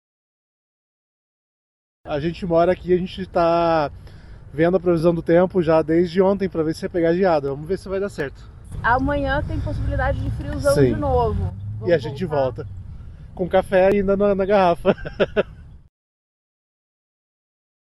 A reportagem da CBN Curitiba esteve no Jardim Botânico e encontrou muita gente que escolheu começar o dia ao ar livre.